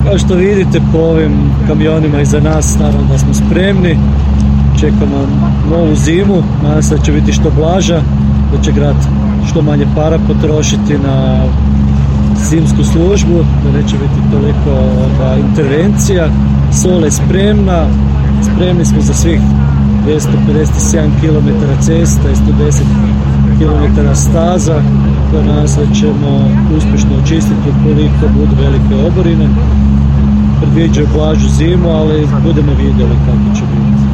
rekao je na smotri održanoj u Koprivnici da je osigurano oko 200 tona soli